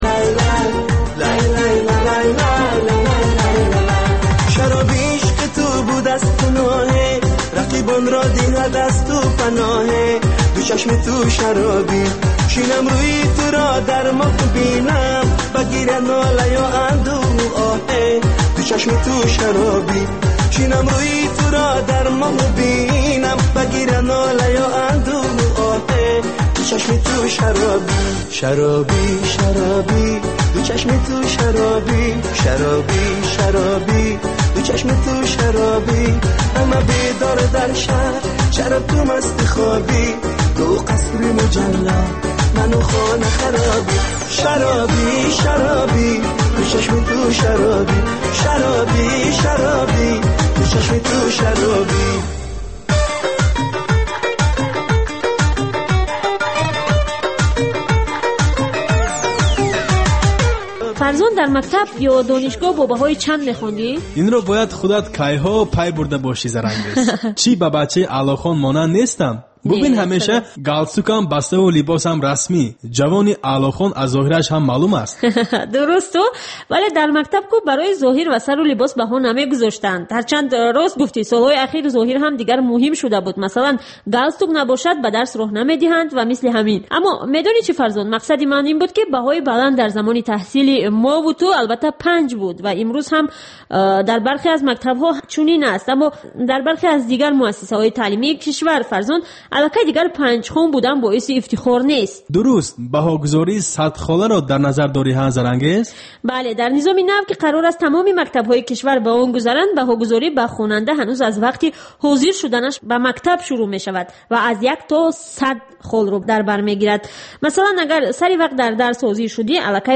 Ахбори фарҳангӣ, гуфтугӯ бо овозхонони саршинос, баррасии консерт ва маҳфилҳои ҳунарӣ, солгарди ходимони ҳунар ва баррасии саҳми онҳо.